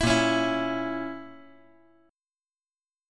Bbdim Chord
Listen to Bbdim strummed